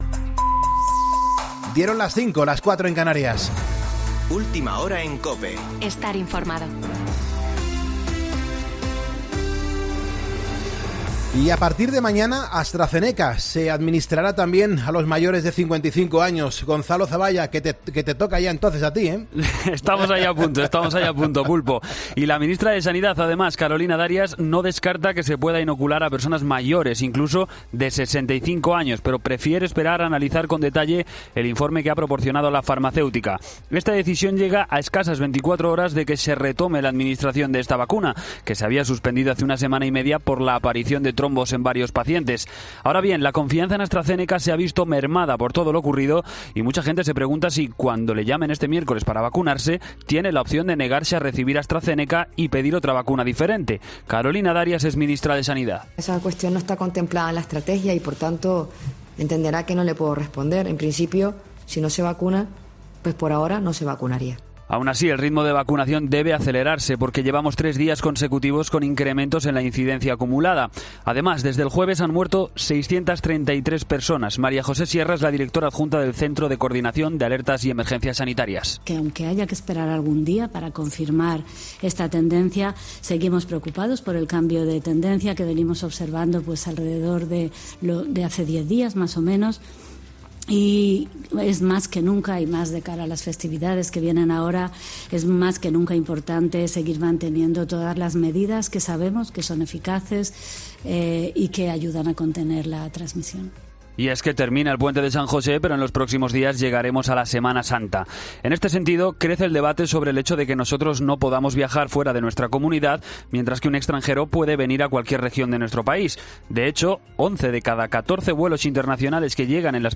Boletín de noticias COPE del 23 de marzo de 2021 a las 05.00 horas